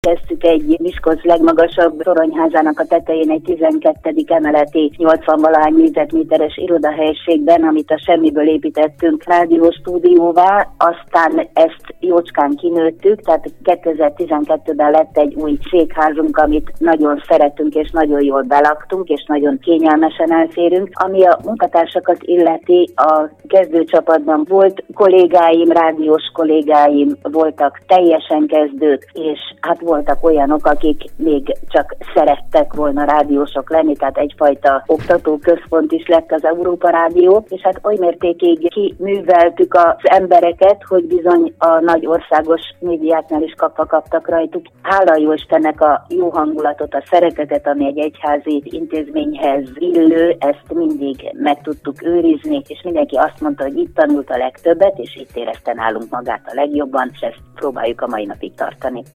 europa_radio_20_riport.mp3